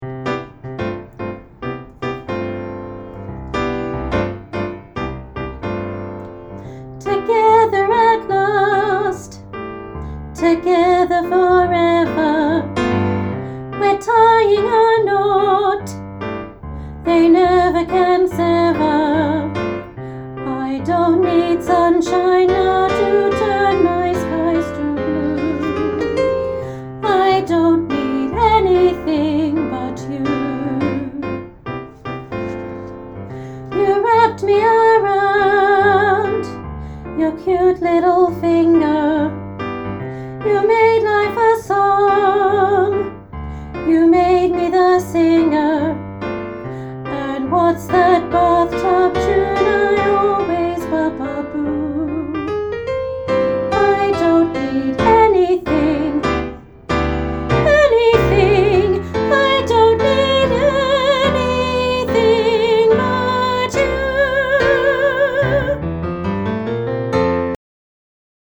Annie – I Don’t Need Anything But You, Jnr Alto Part 2